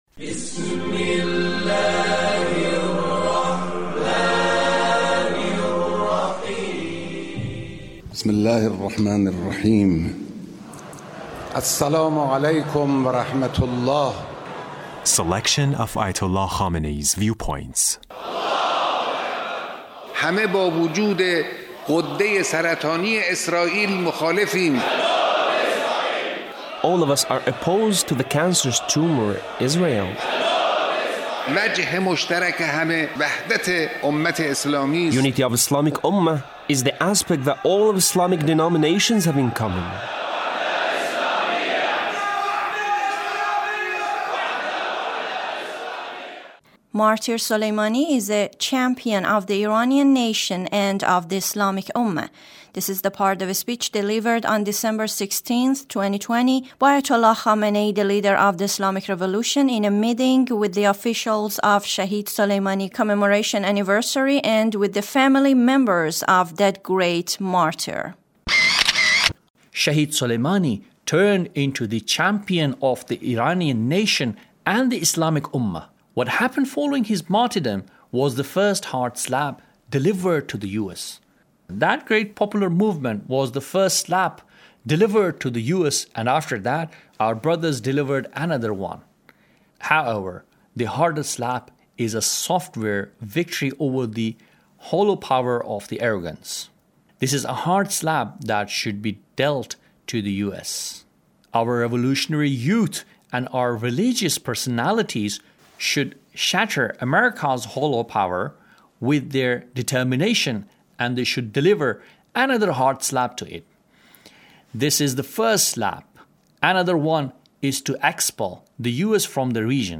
Leader's speech
The Leader's speech in the presence of General Soleimani's Family( Martyr Soleimani is a hero